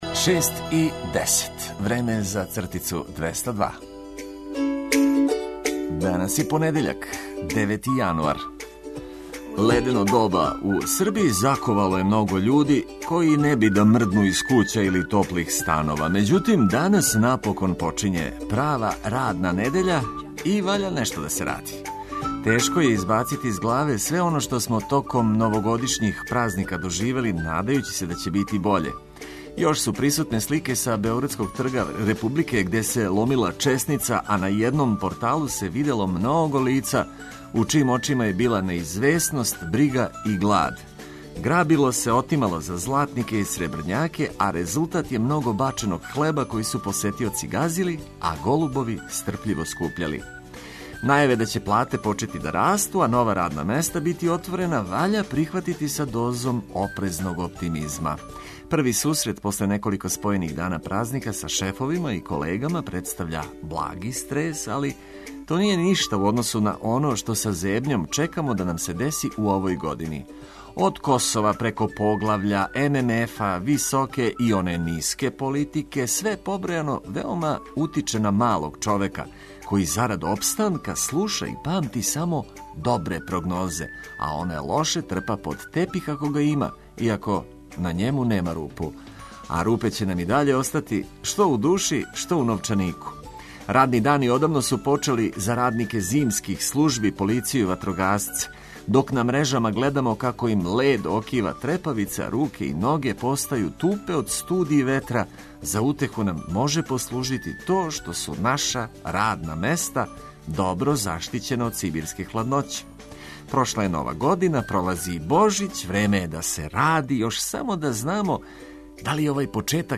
Ако желите да се лепо пробудите, препустите се нашим лепим причама и одличној музици коју смо припремили за вас.